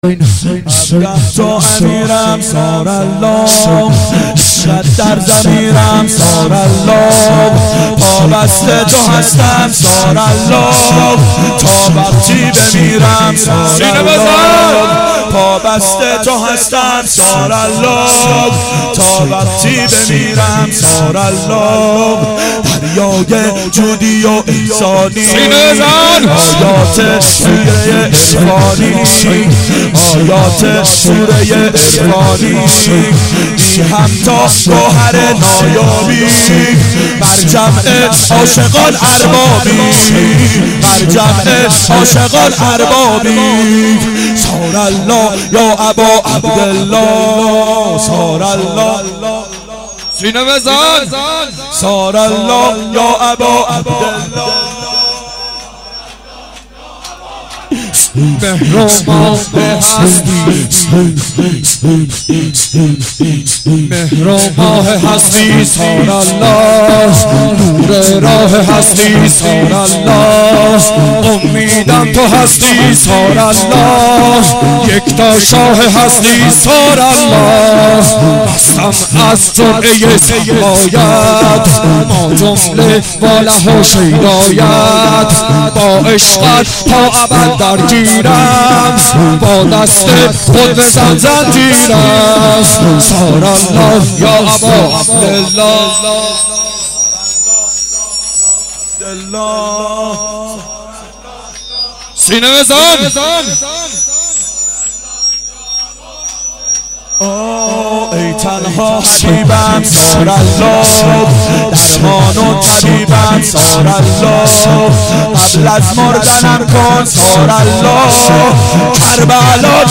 اربعین 90 هیئت متوسلین به امیرالمؤمنین حضرت علی علیه السلام